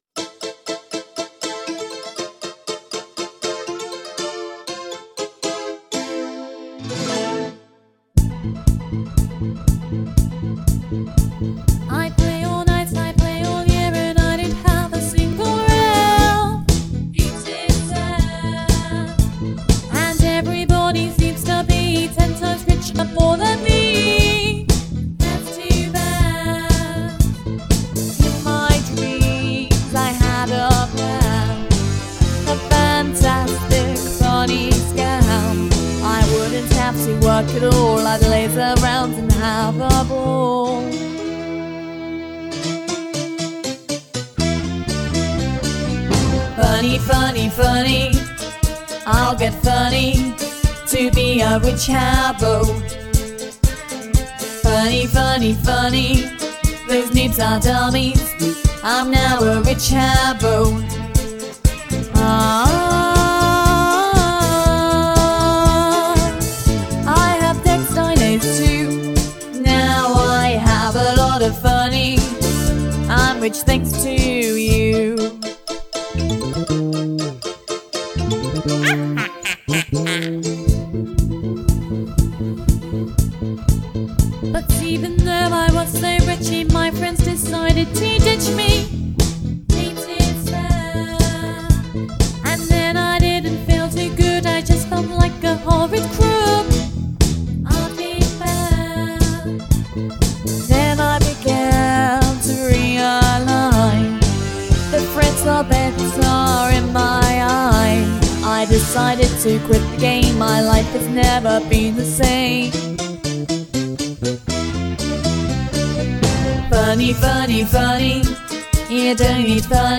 I have no idea why there is a witch cackle either…!